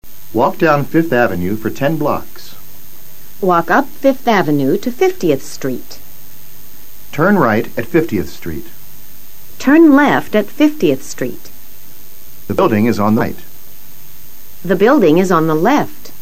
Escucha a los profesores leyendo algunas INSTRUCCIONES.